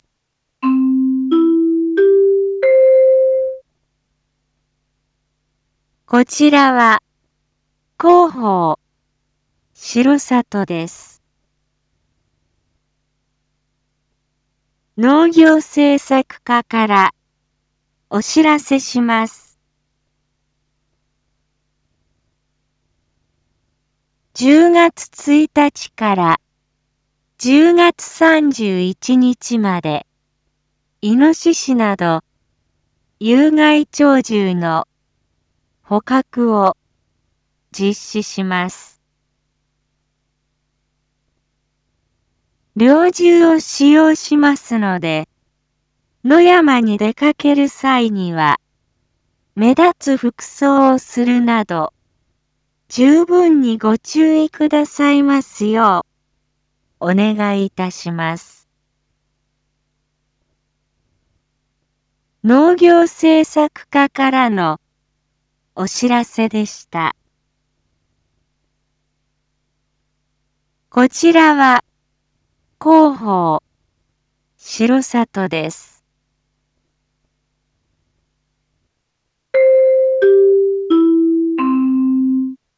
Back Home 一般放送情報 音声放送 再生 一般放送情報 登録日時：2023-10-28 19:01:25 タイトル：有害鳥獣捕獲 インフォメーション：こちらは、広報しろさとです。